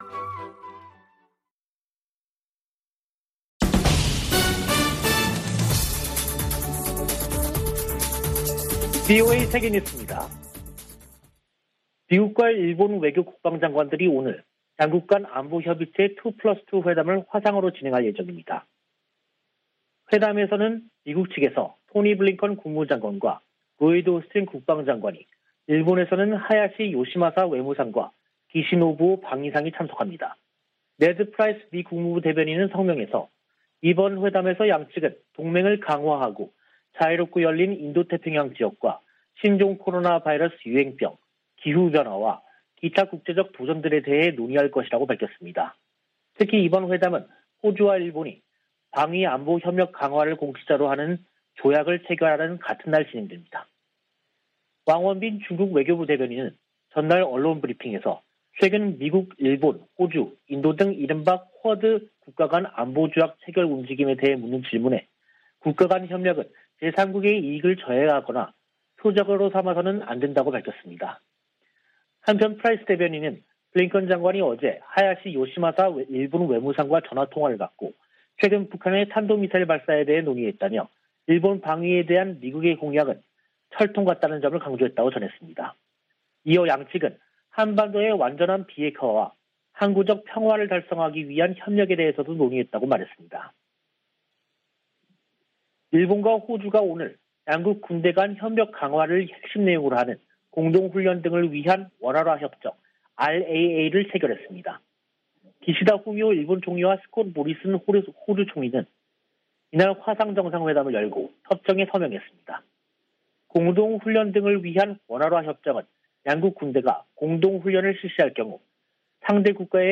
VOA 한국어 간판 뉴스 프로그램 '뉴스 투데이', 2022년 1월 6일 3부 방송입니다. 북한은 5일 쏜 단거리 발사체가 극초음속 미사일이었다고 밝혔습니다. 토니 블링컨 미국 국무장관이 북한의 새해 첫 미사일 도발을 규탄했습니다. 1월 안보리 의장국인 노르웨이는 북한의 대량살상무기와 탄도미사일 개발에 우려를 표시했습니다.